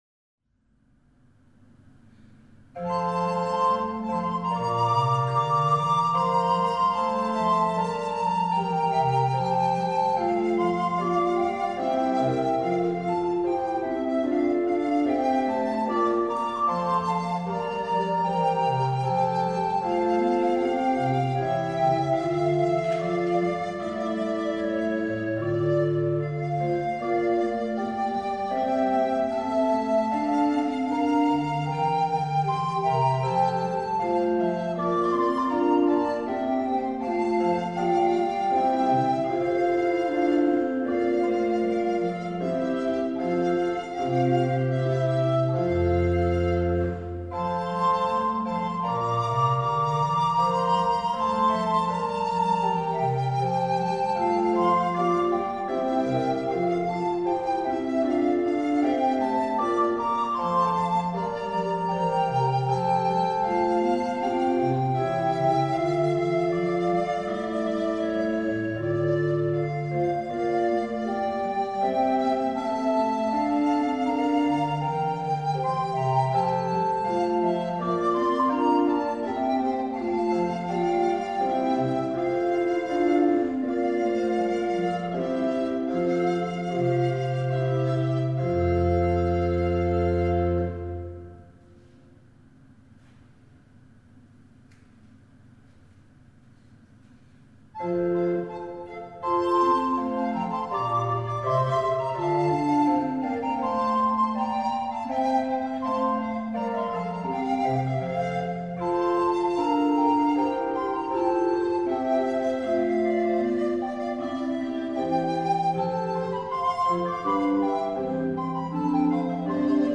Arcangelo Corelli (1653-1713) Trio-Sonate für zwei Altblockflöten und B.C.
Flöten
Orgel